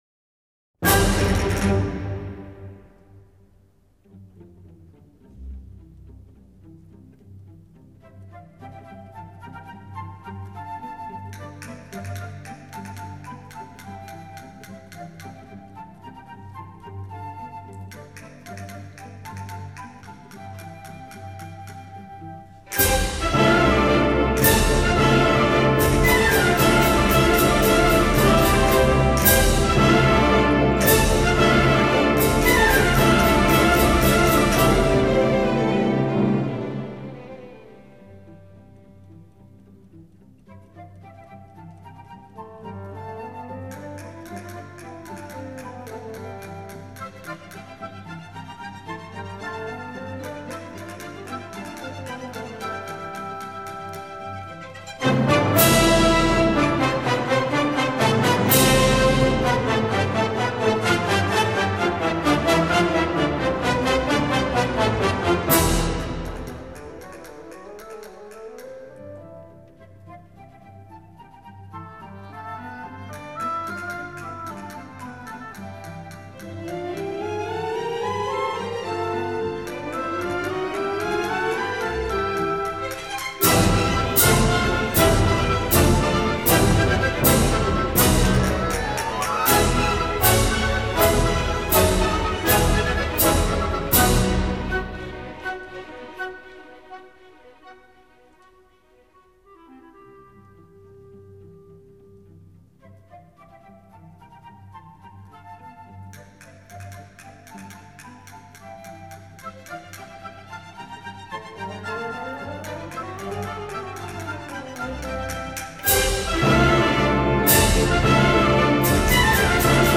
不但音场更为深沈宽阔，画面清晰透明，动态对比强烈，乐器质感也更为真实
这部优美无比的芭蕾组曲选自歌剧中描绘广场狂欢的6首舞曲和另外一首晨曲，场面宏大热烈，充满西班牙风情。